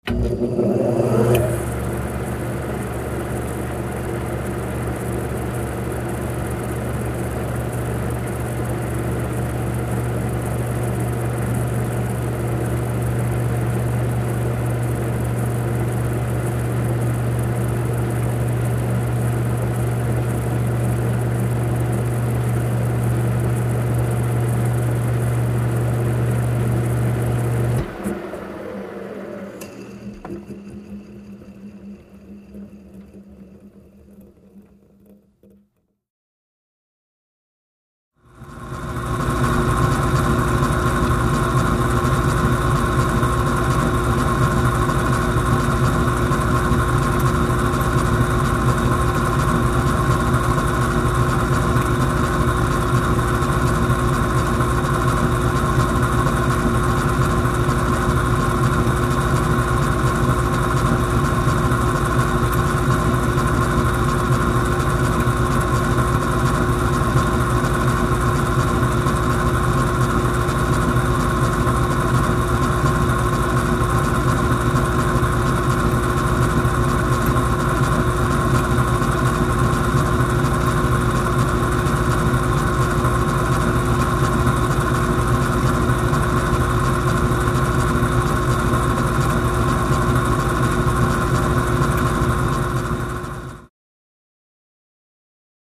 Escalators | Sneak On The Lot
Escalator Motor Operating, Cu Perspective, W Rhythmic Clanks And Squeaks.